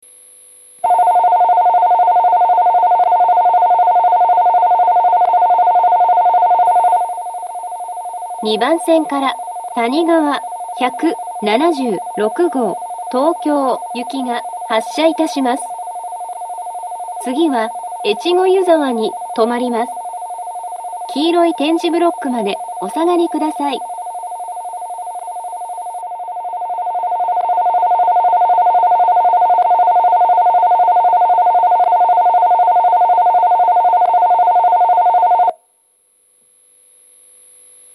２０２１年１０月上旬にはCOSMOS連動の放送が更新され、HOYA製の合成音声による放送になっています。
２番線発車ベル たにがわ１７６号東京行の放送です。